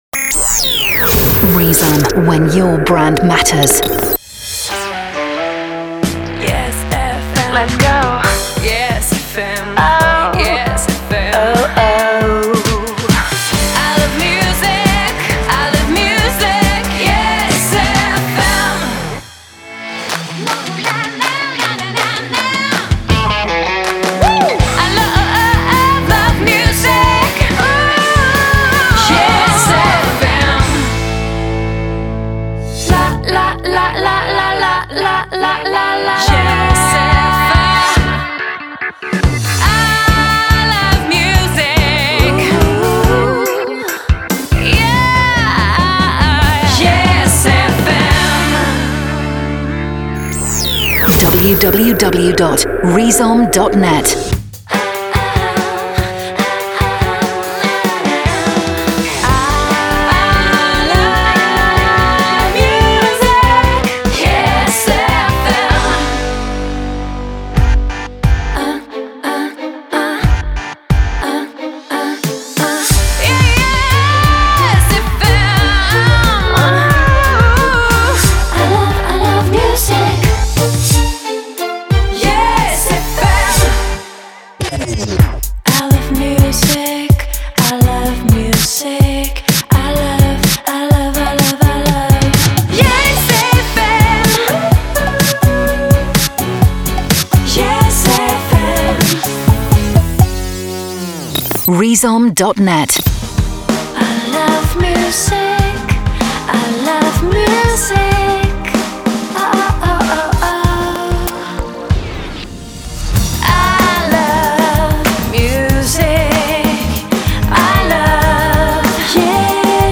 Habillage et jingles chantés pop rock anglo